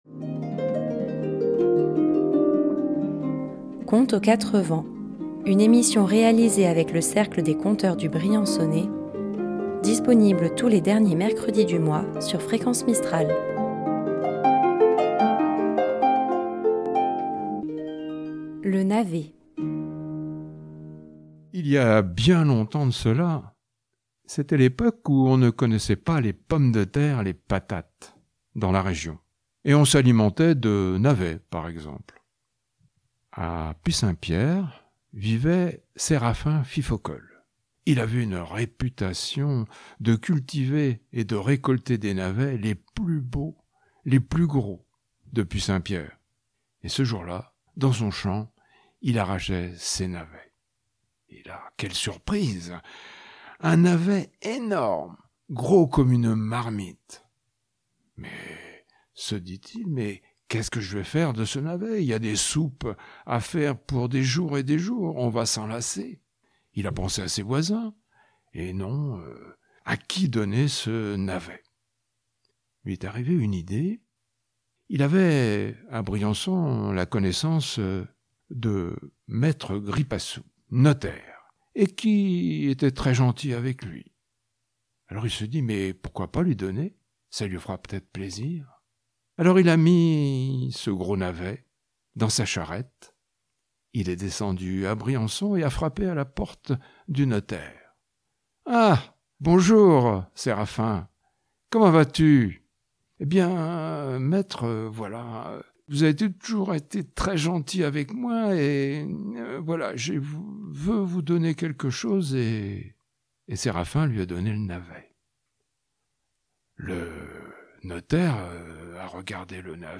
Tous les derniers mercredi du mois, retrouvez le Cercle des conteurs du Briançonnais pour une balade rêveuse.